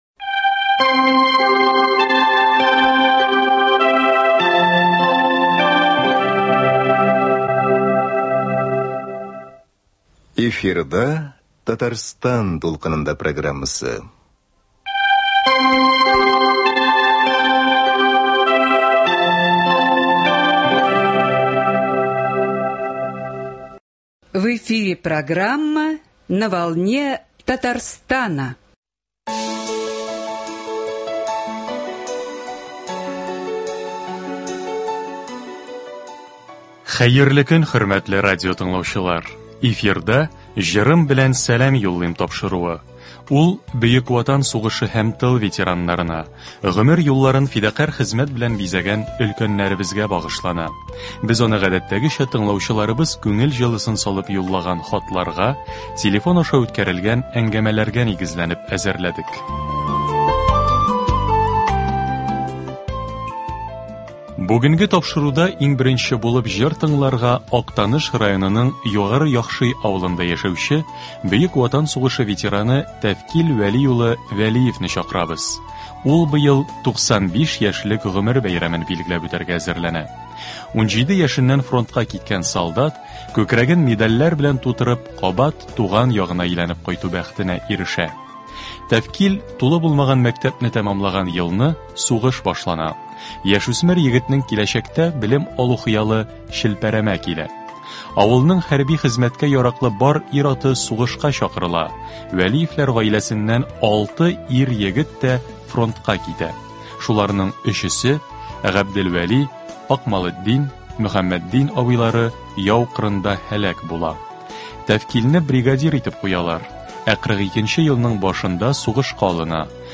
Ветераннар хатлары буенча әзерләнгән музыкаль тапшыру.